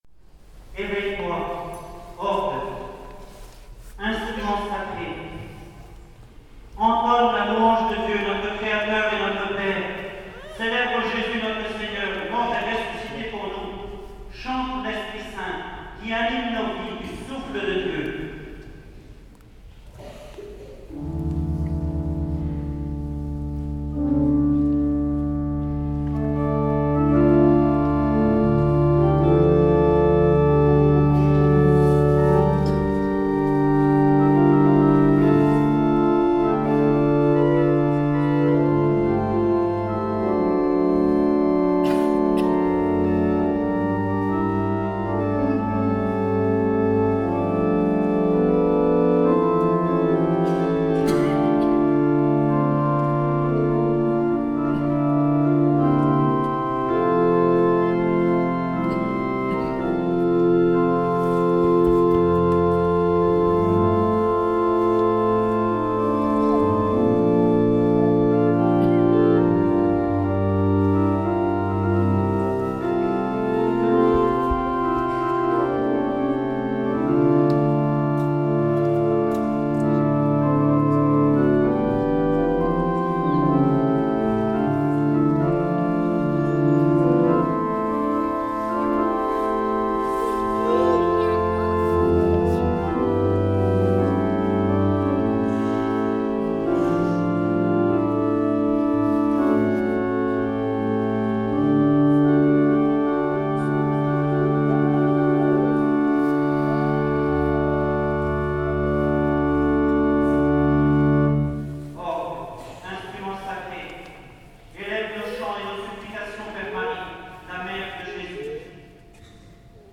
Orgue Nelson Hall de l’église paroissiale Notre-Dame de Penvénan
Extrait de la bénédiction du 22 novembre 2015
orgue solo